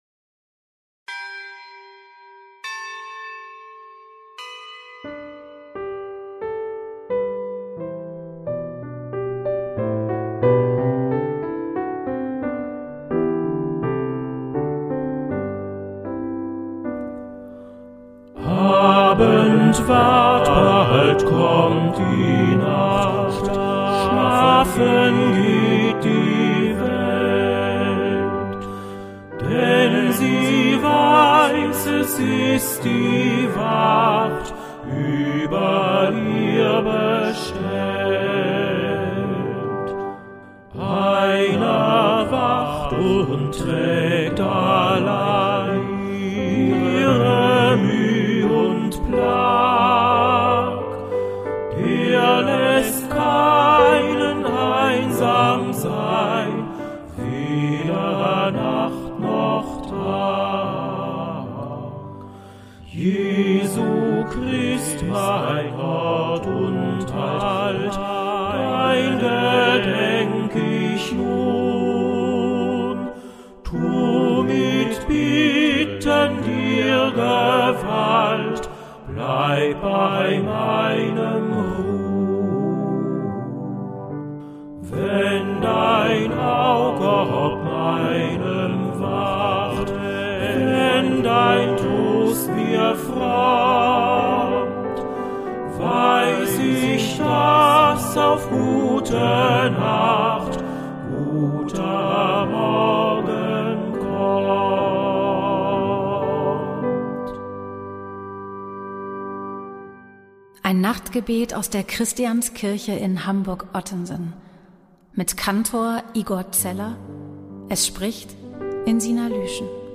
Nachtgebet aus der Christianskirche Hamburg-Ottensen
Gebete, Texte und Gesänge aus der Christianskirche